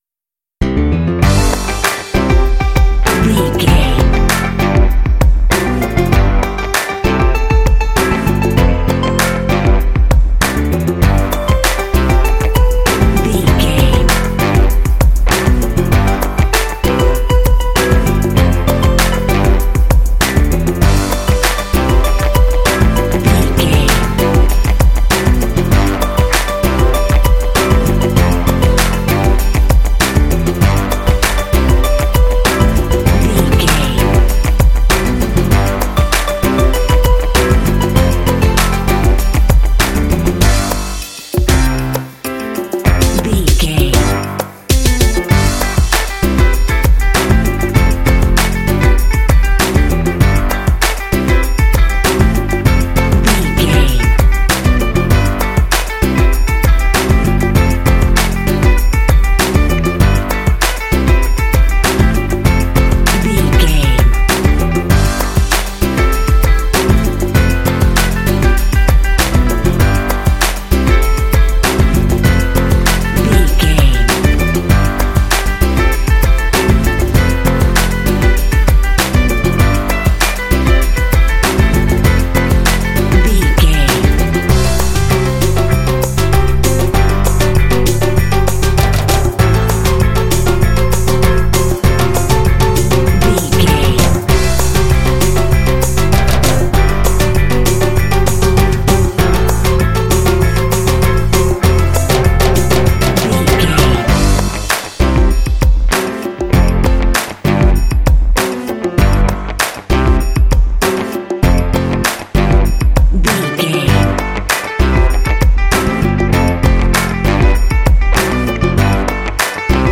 This cute hip hop track is great for kids and family games.
Uplifting
Ionian/Major
bright
happy
bouncy
piano
bass guitar
electric guitar
electric organ
drums
Funk